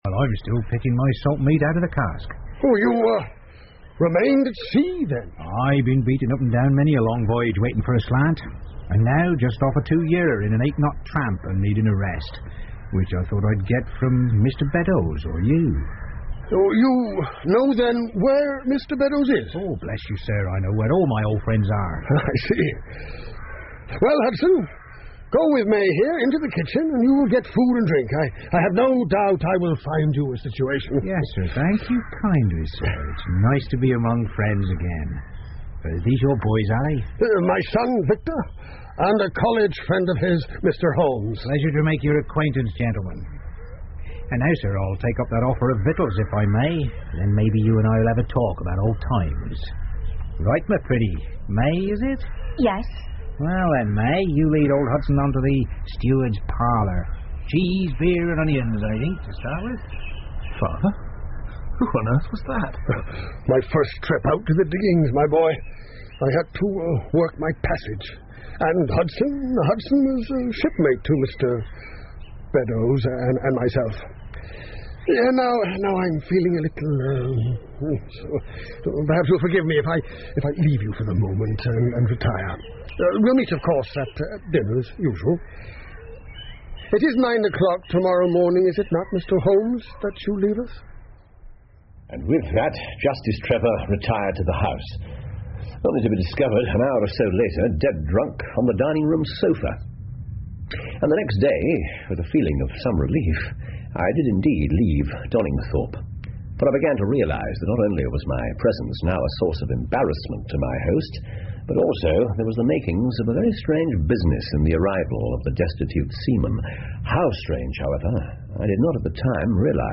福尔摩斯广播剧 The Gloria Scott 4 听力文件下载—在线英语听力室